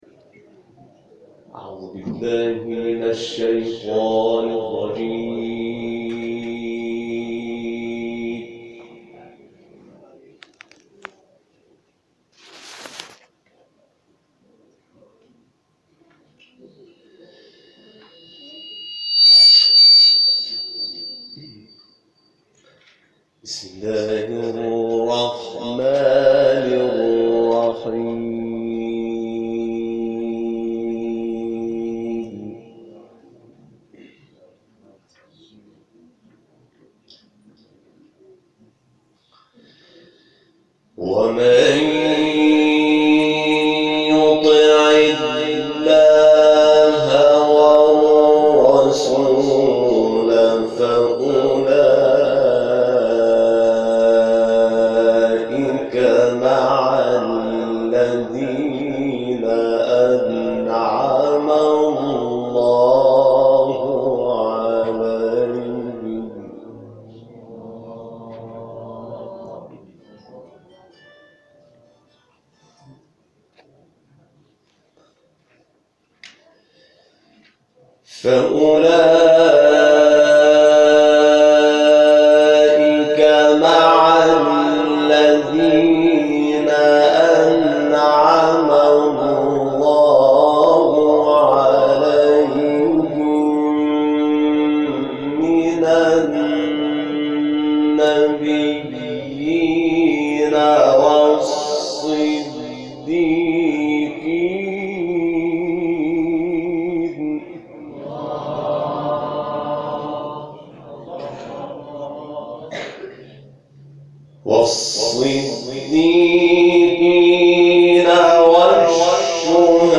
تلاوت شاکرنژاد در محفل یادبود شهدای مدافع حرم
گروه فعالیت‌های قرآنی: حمید شاکرنژاد، قاری بین‌المللی قرآن کریم در محفل انس با قرآن یادبود شهدای مدافع حرم که شامگاه هشتم اردیبهشت‌ماه در مسجد الزهرا(س) منطقه فاطمیه مشهد برگزار شد، به تلاوت آیات وحی پرداخت.
تلاوت حمید شاکرنژاد